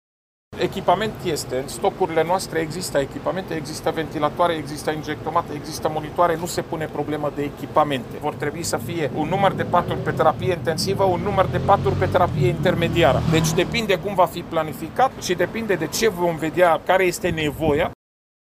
Anunţul a fost făcut, astăzi, de către secretarul de stat în Ministerul Afacerilor Interne, doctorul Raed Arafat.